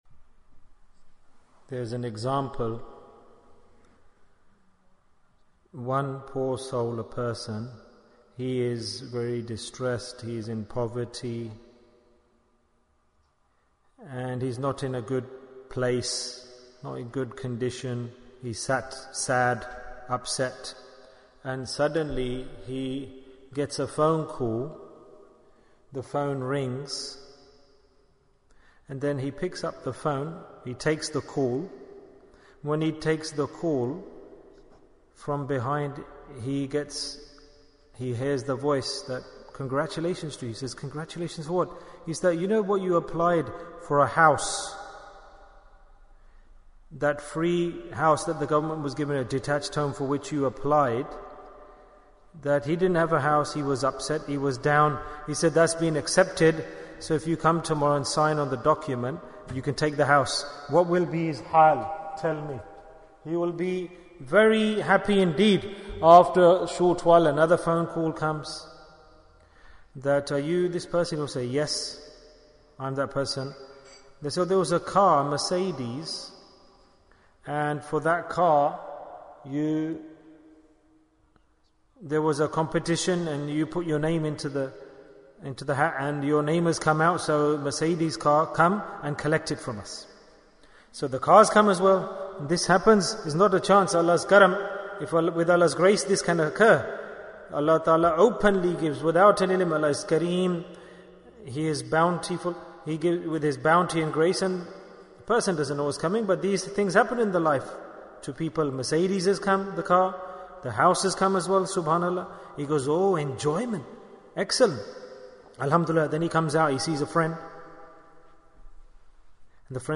Message for the Day of Ashoorah Bayan, 38 minutes19th August, 2021